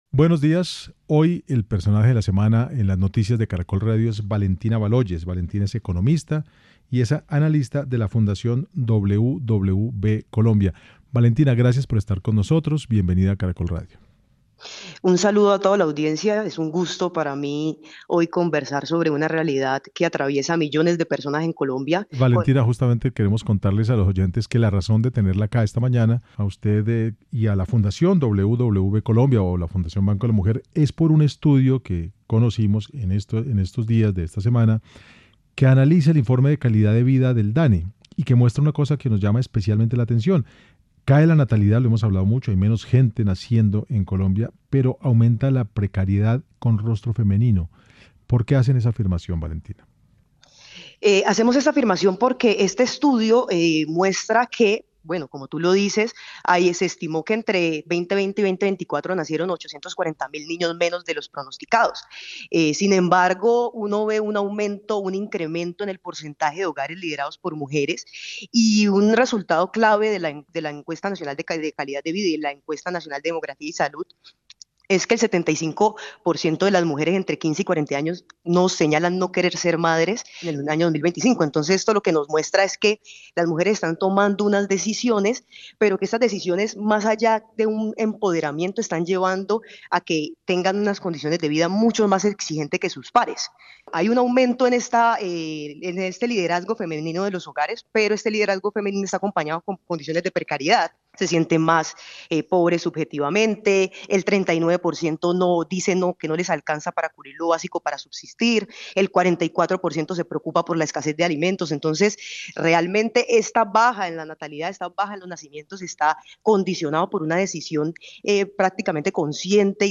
Finalmente, la economista hizo un llamado a repensar el rol de los hombres en el cuidado y a entender que la solución no pasa por presionar decisiones individuales, sino por construir entornos dignos y equitativos: “El riesgo como país no está en las decisiones que tomen las mujeres si la mujer decide o no ser madre. El riesgo está en no poder adaptarnos a este ritmo demográfico que se nos está marcando.”.